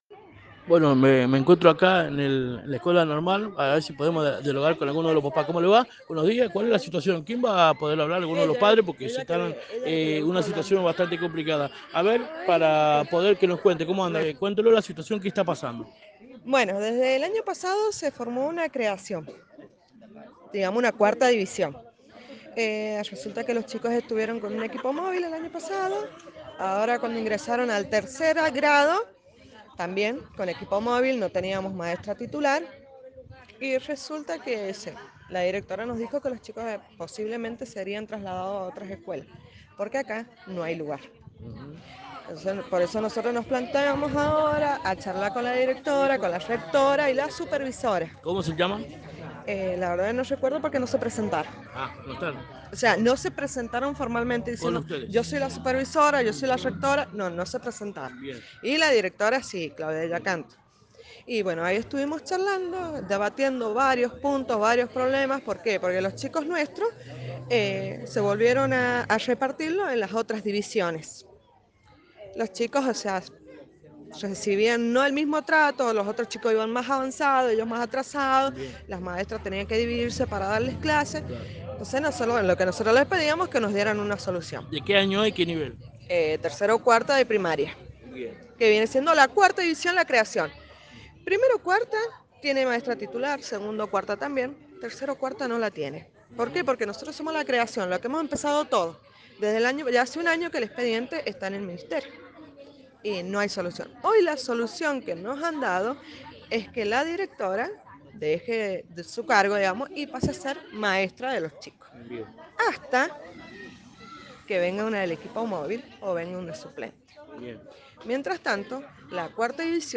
AUDIO NOTA .LOS PADRES DE LA NORMAL,RECLAMAN Y EVITAN QUE SU HIJOS SEAN REUBICADOS